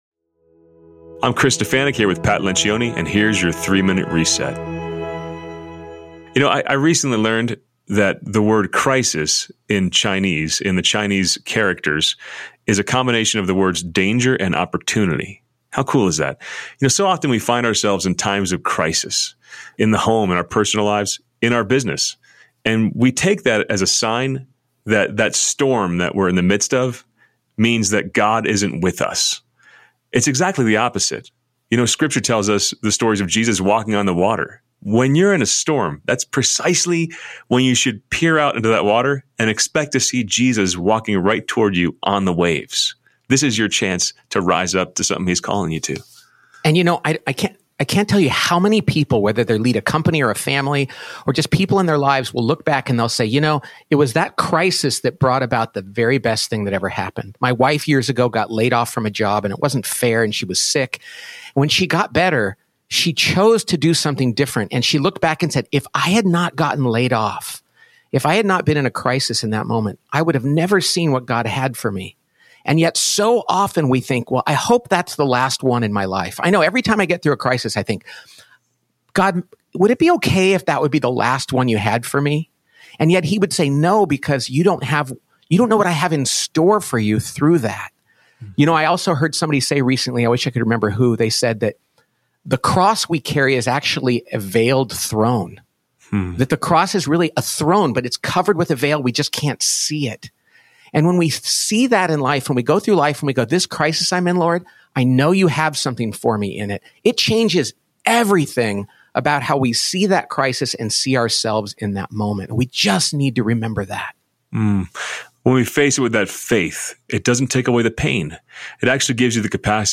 a daily reflection for Christians in the workplace